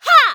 qyh发力4.wav 0:00.00 0:00.35 qyh发力4.wav WAV · 31 KB · 單聲道 (1ch) 下载文件 本站所有音效均采用 CC0 授权 ，可免费用于商业与个人项目，无需署名。
人声采集素材